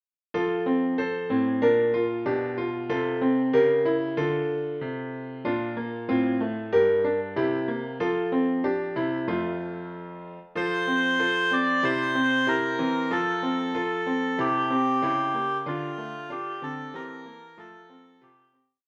deutsches Weihnachtslied